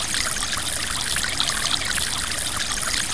AM  SWAMP 1.wav